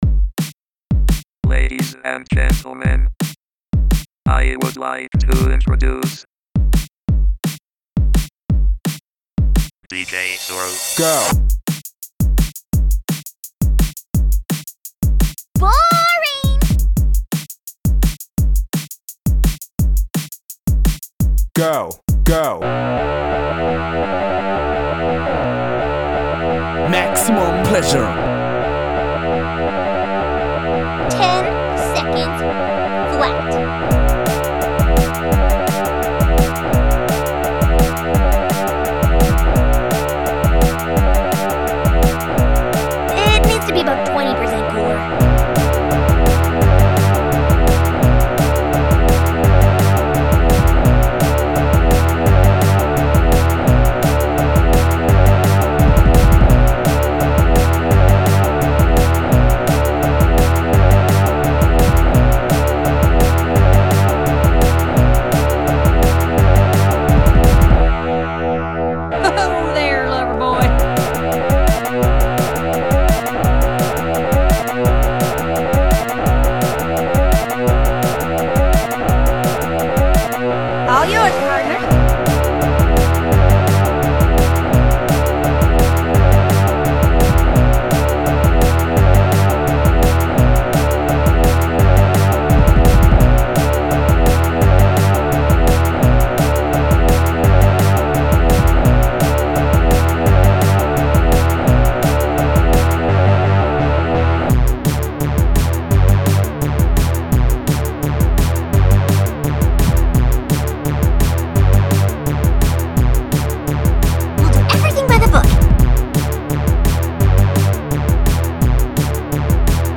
Drum n' Bass Remix